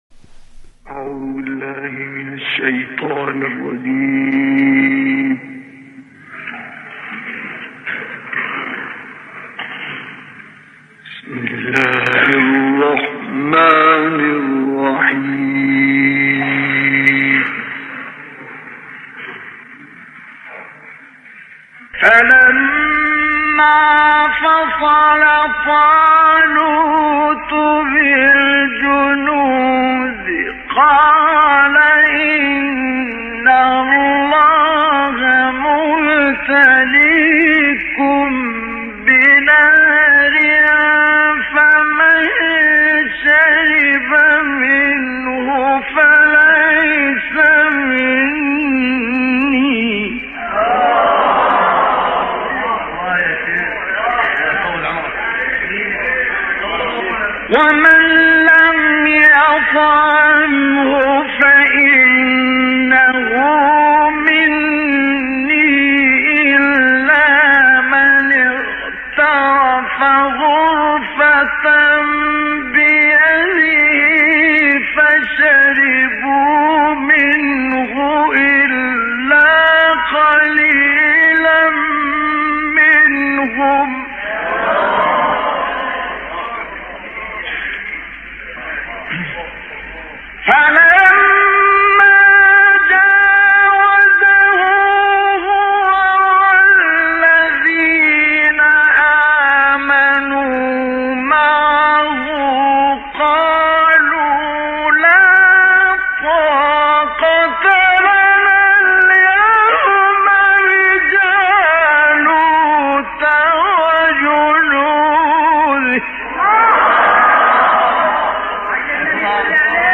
تلاوت مصطفی اسماعیل  از سوره مبارکه بقره
بهره‌گیری از اوج مقام نهاوند برای اتمام تلاوت